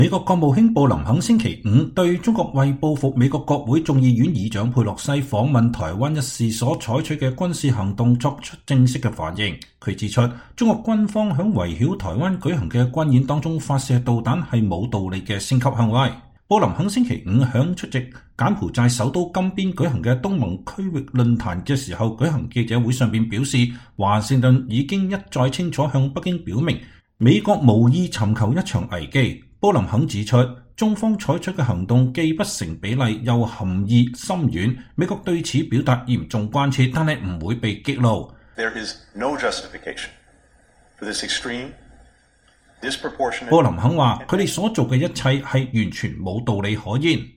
美國務卿布林肯在金邊舉行的記者會上講話。